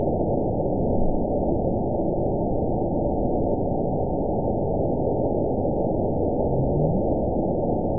event 920101 date 02/22/24 time 21:07:01 GMT (3 months, 1 week ago) score 8.30 location TSS-AB07 detected by nrw target species NRW annotations +NRW Spectrogram: Frequency (kHz) vs. Time (s) audio not available .wav